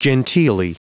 Prononciation du mot genteelly en anglais (fichier audio)
genteelly.wav